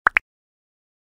Звуки нажатия кнопки
Звук мультяшного клика по кнопке